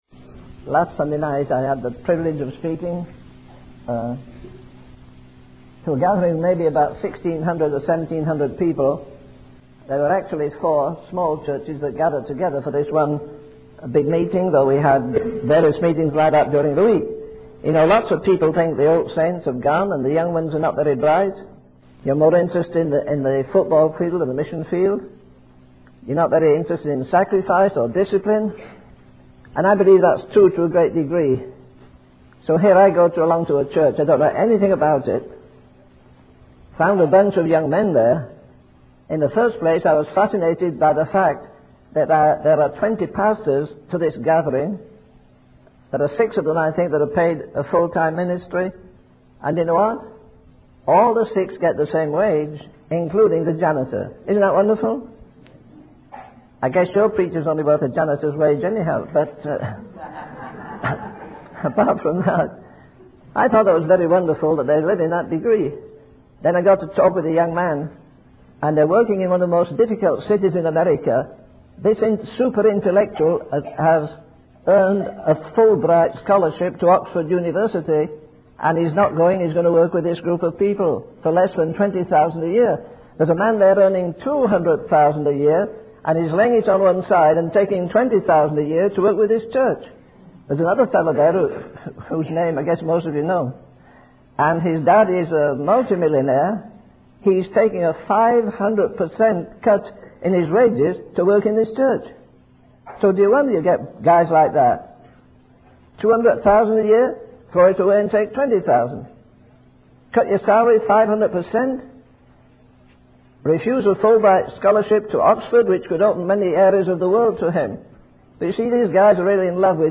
In this sermon, the preacher emphasizes the importance of true love and sacrifice. He highlights that true love seeks the ultimate pleasure of the one it loves, and as believers, our ultimate purpose is to give pleasure to God.